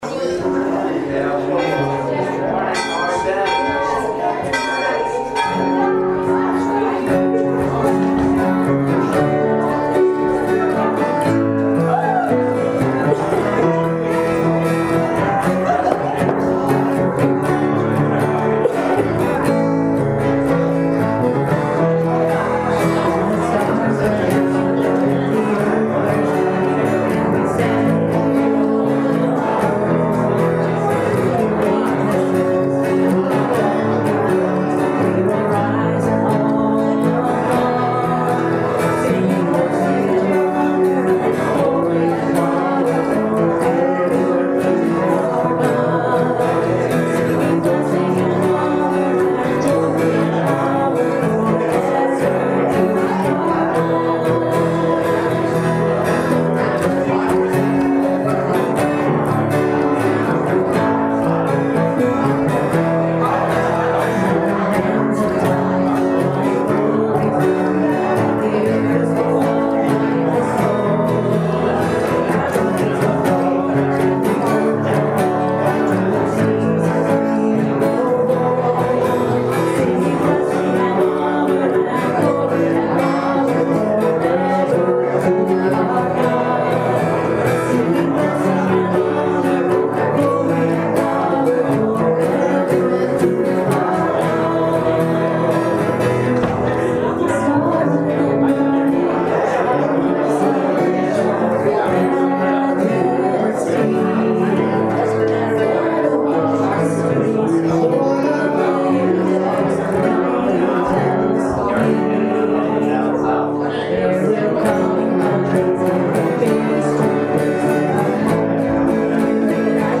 September 18th, 2016 Service Podcast
Morning Message: Following the Rules